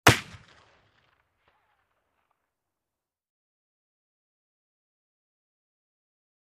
Rifle Shot | Sneak On The Lot